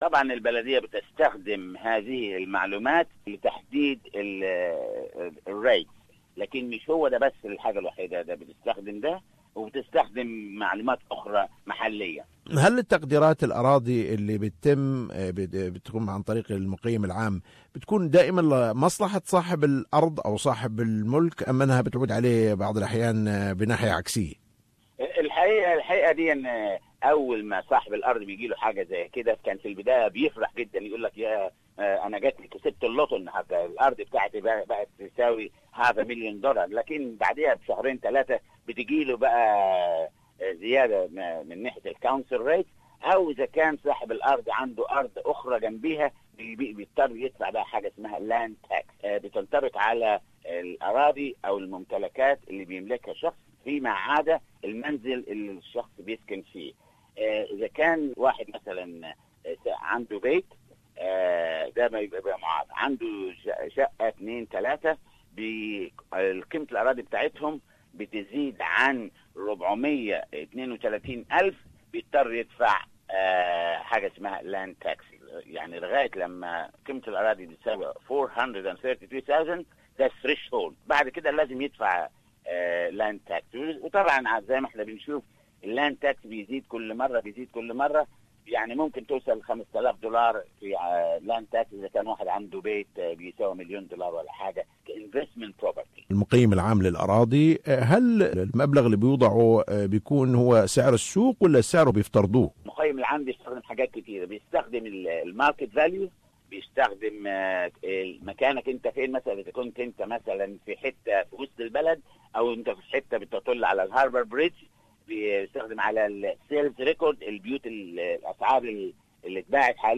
تحدث الى الرئيس السابق لبلدية أشفيلد السيد موريس منصور وسأله عن مدى اعتماد البلديات على تقدير المقّيم العام للأراضي وهل هذا التقييم يعود بالنفع على مالكي الأراضي .